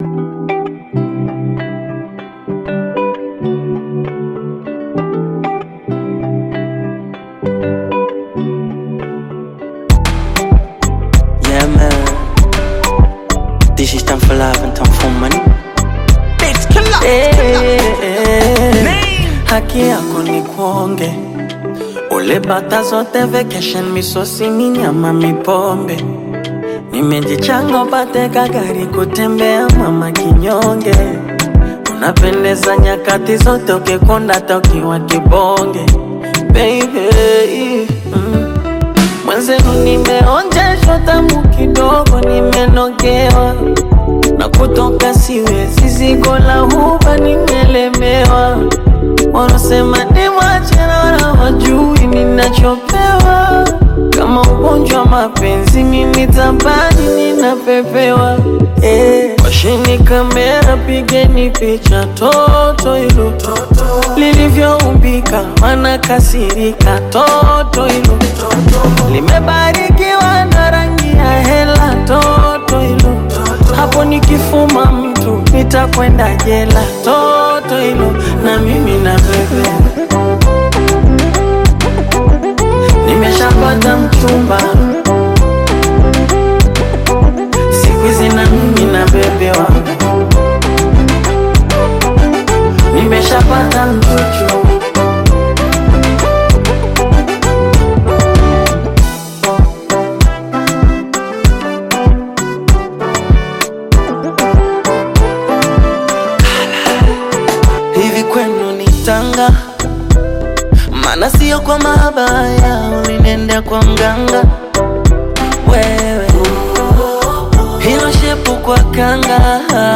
The song arrives with vibrant energy
wrapped in playful lyrics and Afro-fusion beats.
Bongo Flava